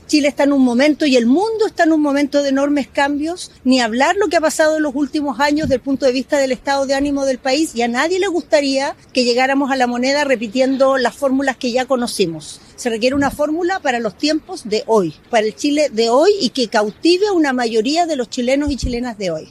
A las afueras del metro Universidad de Chile, la abanderada del Socialismo Democrático, Carolina Tohá, también partió la jornada con un volanteo, donde aseguró que las próximas semanas serán “decisivas”.